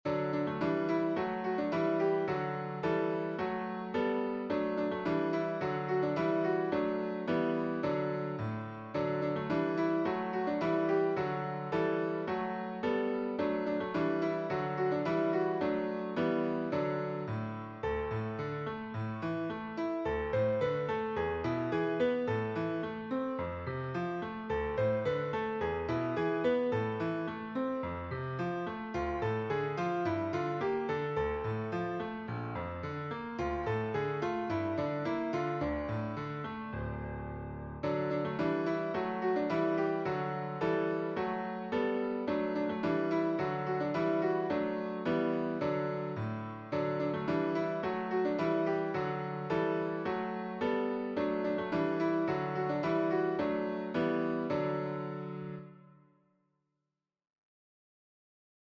Hebrew folk song
The song can also be sung in a round.